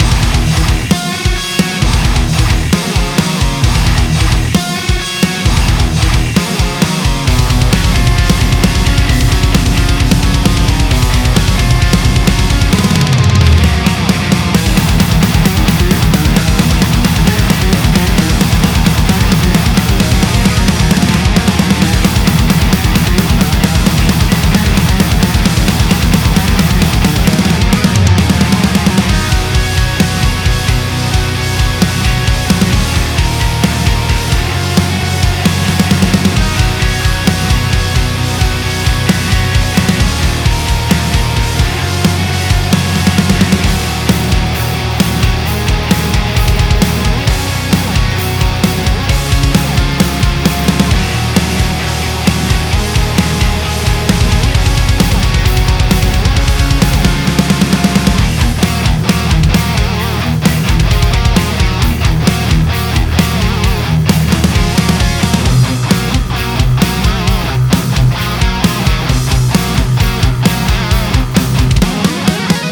Я вот сейчас свожу совершенно типовой металкор ...
Все , гитары сразу звучат как должны звучать в этом стиле ..